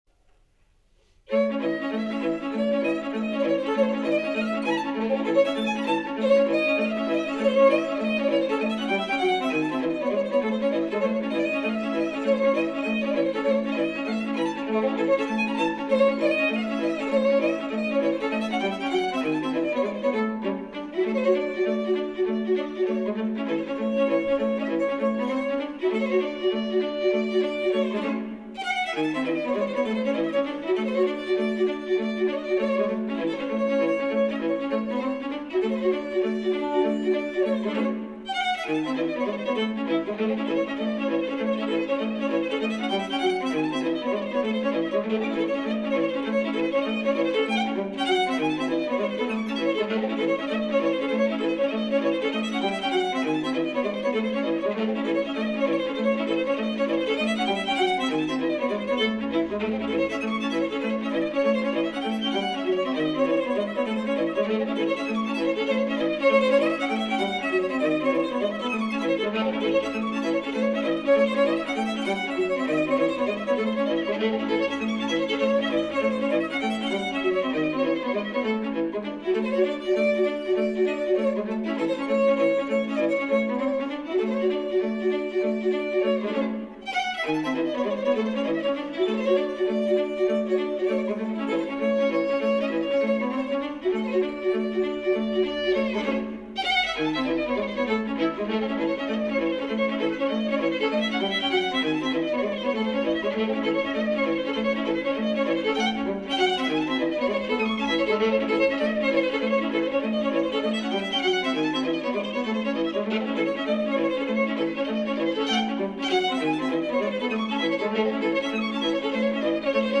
Eine rumänische „Sârbă“, arrangiert für Violine und Bratsche:
sarba-violino-viola1.mp3